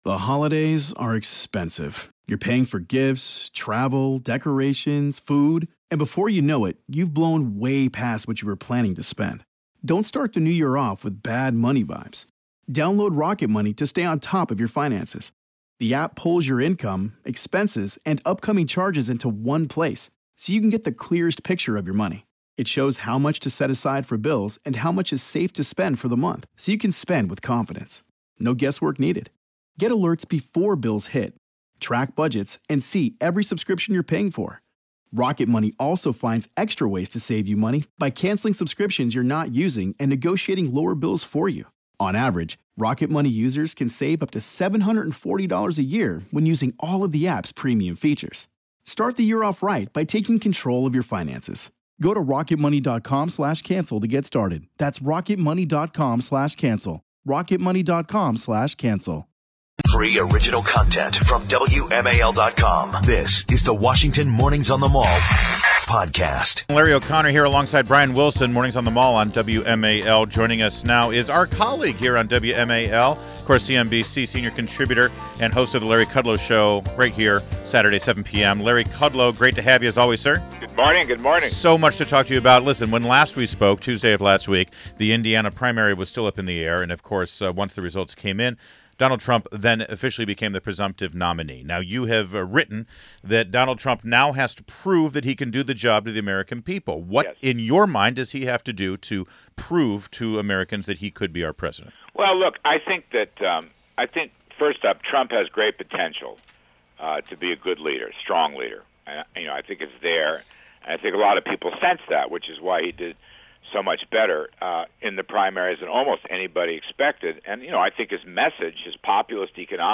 WMAL Interview: Larry Kudlow 05.10.16
INTERVIEW -- LARRY KUDLOW - CNBC Senior Contributor and host of The Larry Kudlow Show on WMAL Saturdays at 7 pm